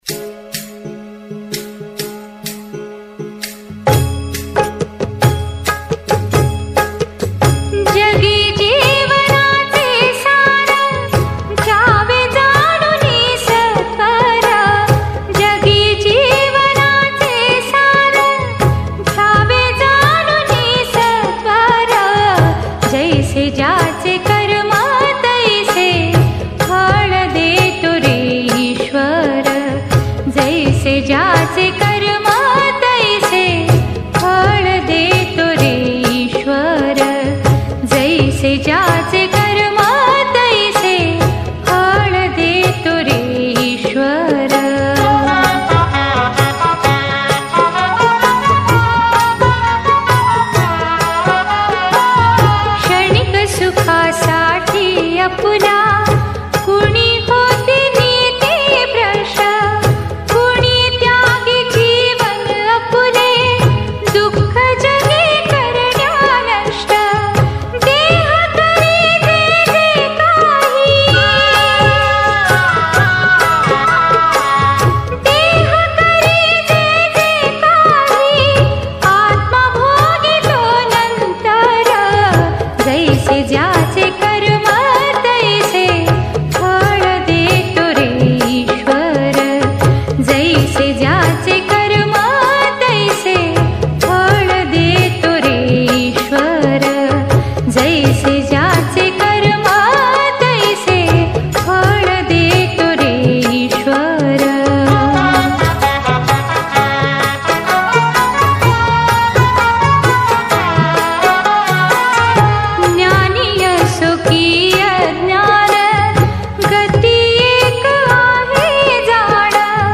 Female Version
• Speaker: Singer